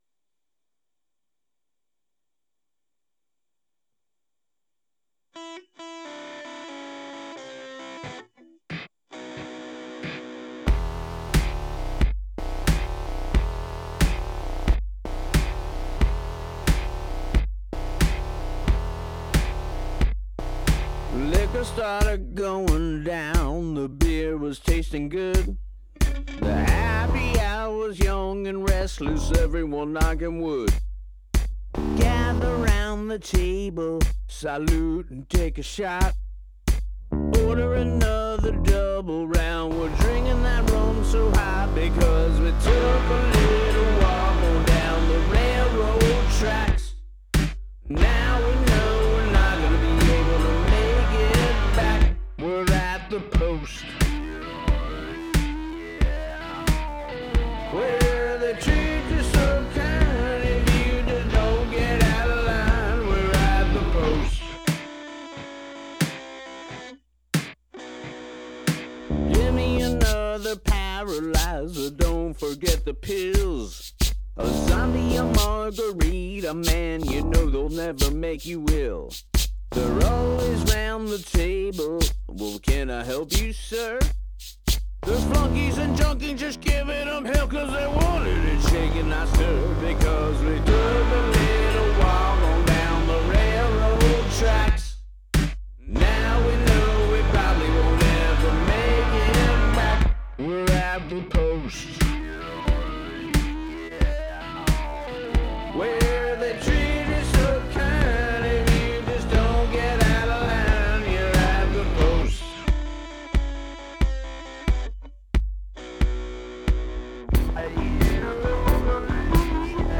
The Post: “Dirty Blues Rock” mixdown
I ramped up the heavy and beautiful saturation quite a bit while retaining the swagger of a bars-blues band.